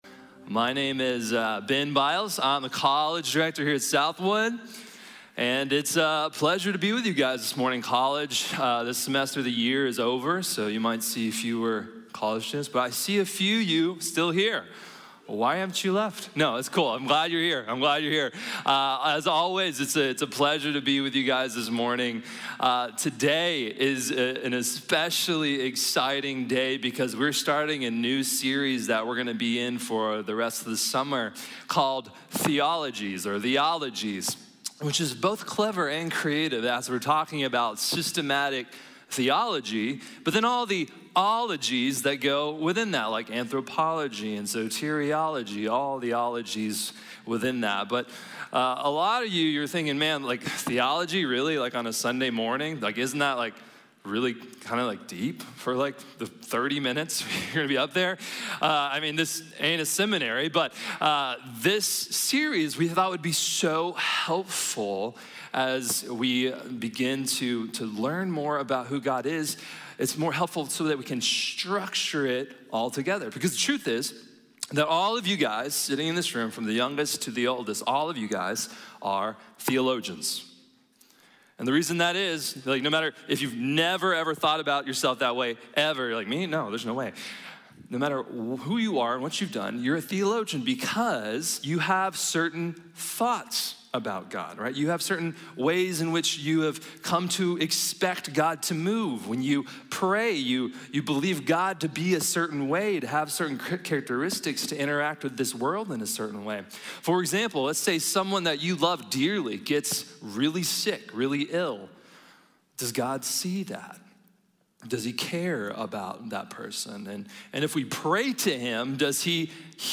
Trinitarismo | Sermón | Iglesia Bíblica de la Gracia